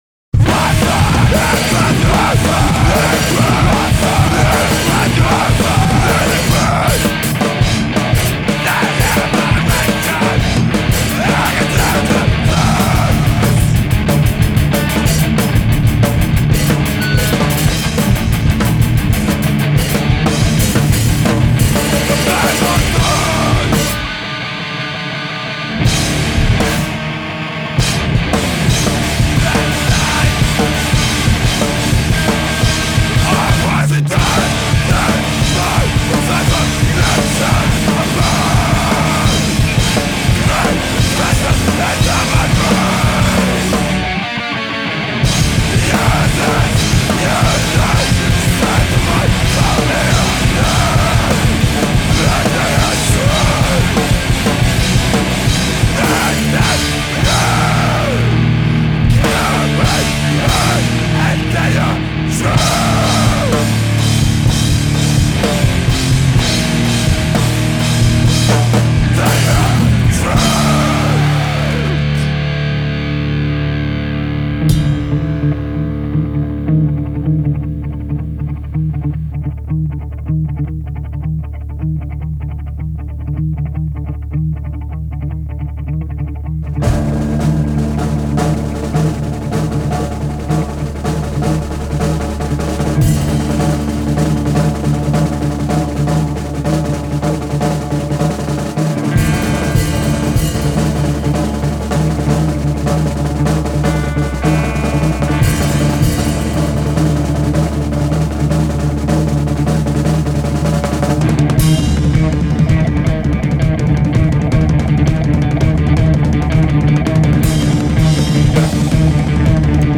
Bass
at Semaphore Studios in Chicago, IL.
Filed under: Hardcore